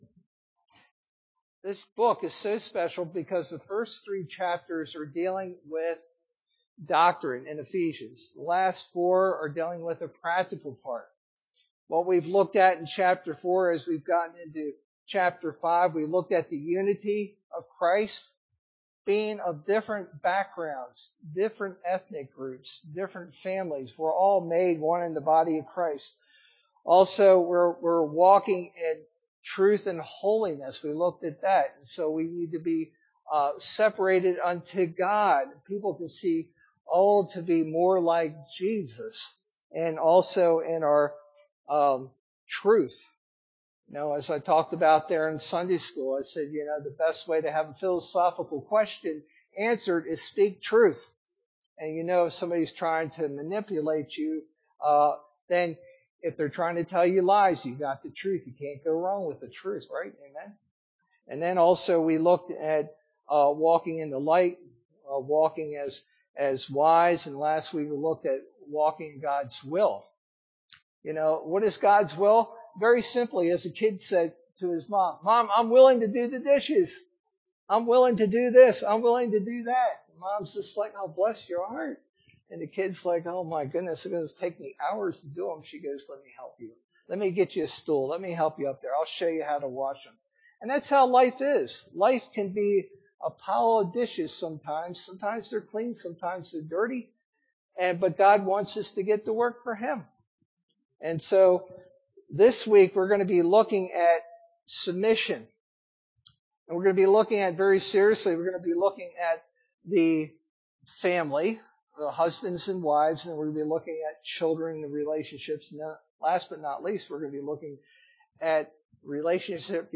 Sermon verse: Ephesians 5:22-6:9